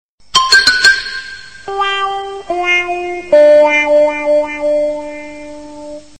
SFX短暂可爱的信息提示音音效下载
SFX音效